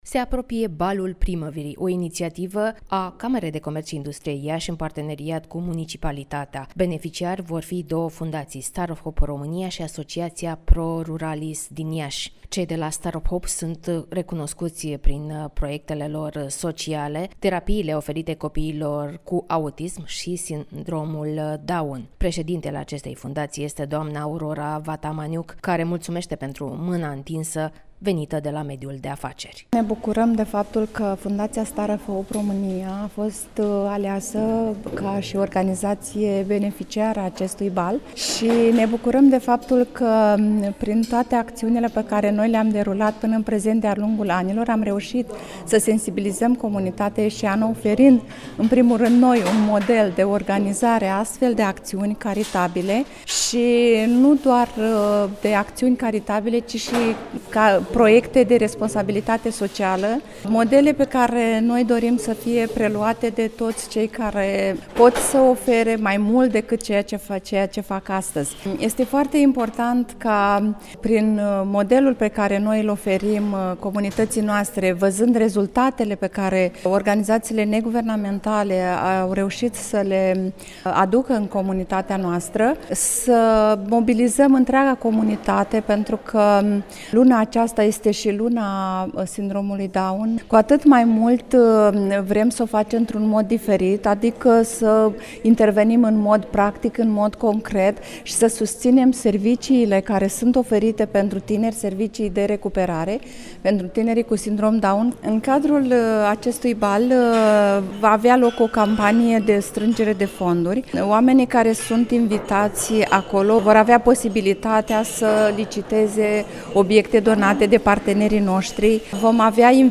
(REPORTAJ) Se apropie Balul Primăverii